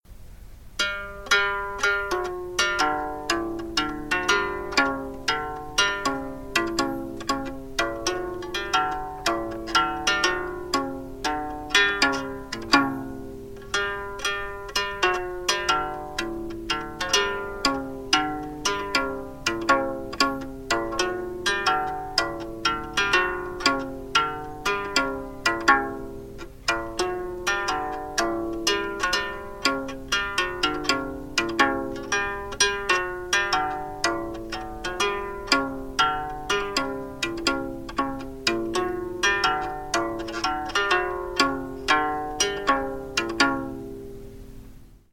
トンコリ演奏